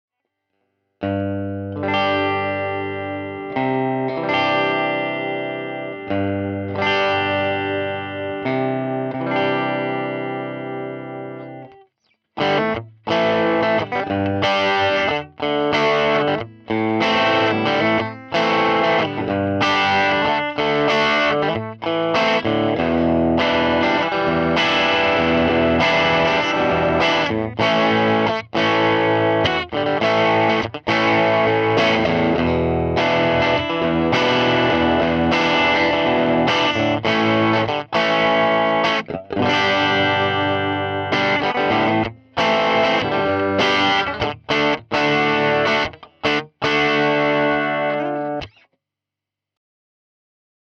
Gitarren-Aufnahme: Beliebteste Speaker / Amp-Ketten fürs Recording?
Meine Kette ist aktuell: Fender Telecaster Ultra > Fender Deluxe Reverb > m88/md421 > CAPI Vp28. Zuhause muss ich allerdings auf die Grossmann Box ausweichen.
Aktuell klingt das so: Bisschen belegt halt: Ein Mikro alleine klingt ziemlich kacke, aber zwei zusammen und im Kontext geht es schon in die richtige Richtung.